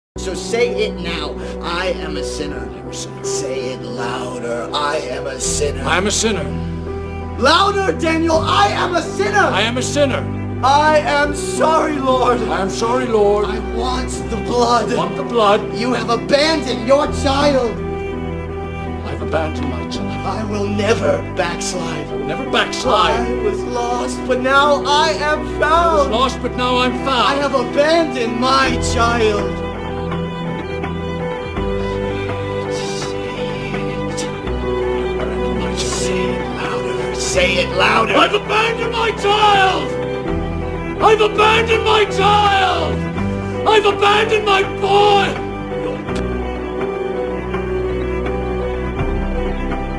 Yeah screaming means good acting 😀
loud ≠ good acting